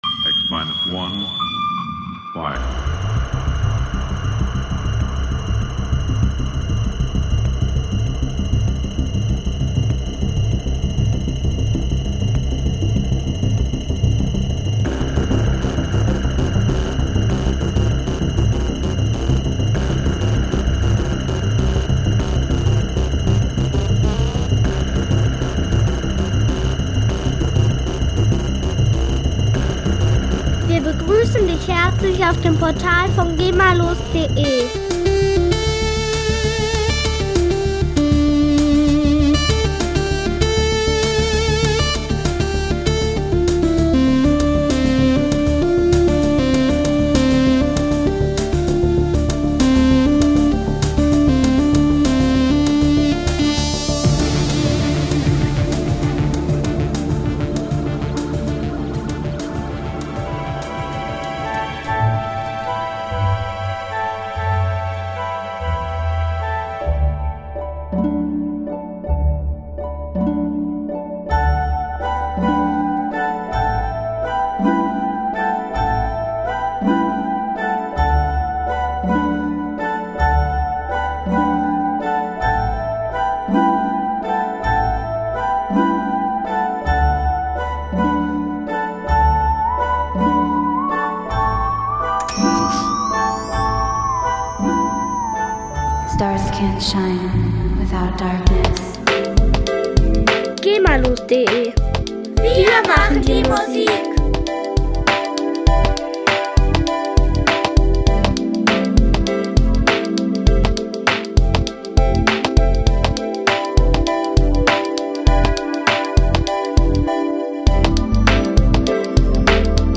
GEMA-freie Sci-Fi Musik
Musikstil: Space Music